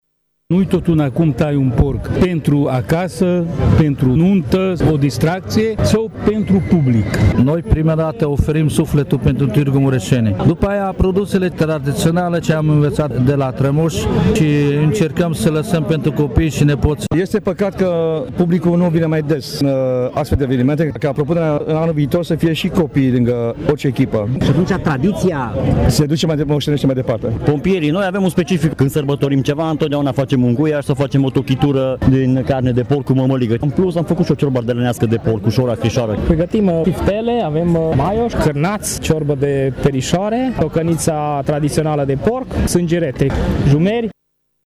Bucătarii au subliniat importanța păstrării tradiției, iar fiecare s-a lăudat cu felurile de mâncare pe care le-au gătit: guiaș, tochitură cu mămăligă, ciorbă ardelenească, chiftele, maioș, cârnați, ciorbă de perișoare, tocăniță, sângerete și jumări: